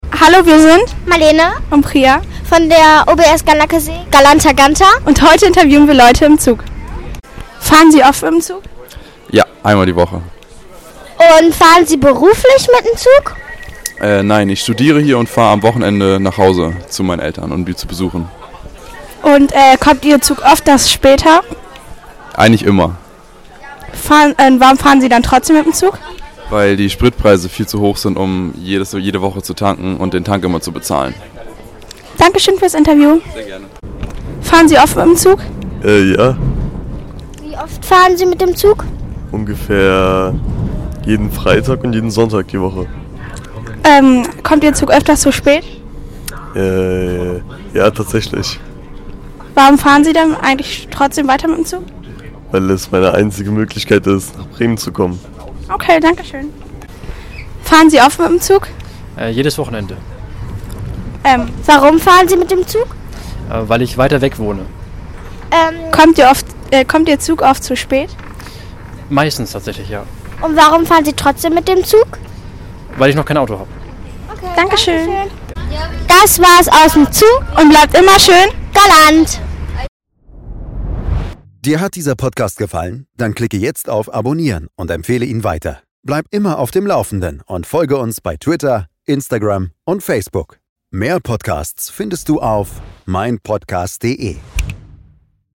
Schon wieder Verspätung?! Wir fragen Fahrgäste im Zug: Warum fährt überhaupt noch jemand Bahn?
Zug-Verspätung? Wir fragen Fahrgäste, warum sie trotzdem weiter mit der Bahn fahren, spannende Antworten direkt aus dem Zug!
schon-wieder-verspaetung-wir-fragen-fahrgaeste-im-zug-warum-faehrt-ueberhaupt-noch-jemand-bahn.mp3